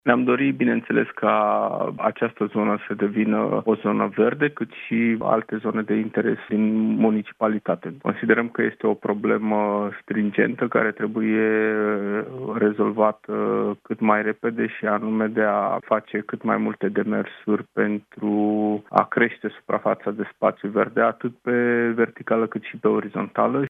Consilierul PSD, Adrian Vigheciu: „Considerăm că este o problemă stringentă care trebuie rezolvată cât mai repede”